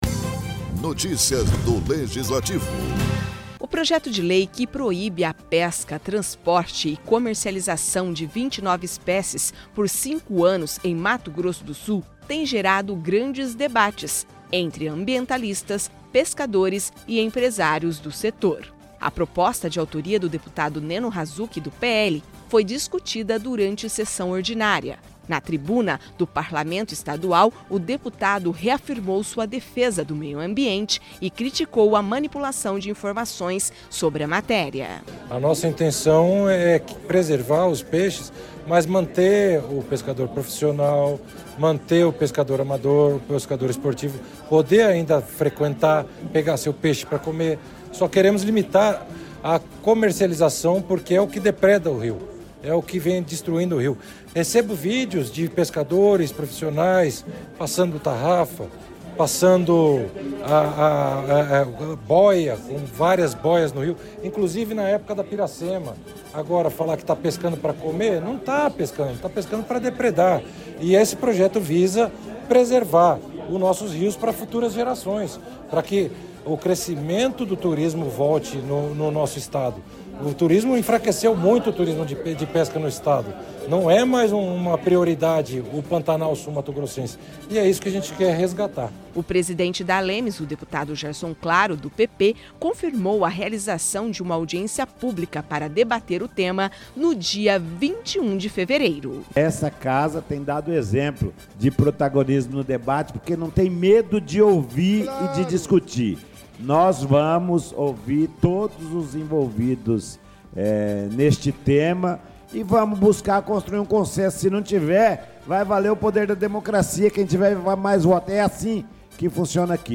Durante sessão ordinária, o deputado Neno Razuk (PL) fez uso da tribuna da Assembleia Legislativa de Mato Grosso do Sul (ALEMS), para debater uma proposta que dispõe sobre a pesca no Estado.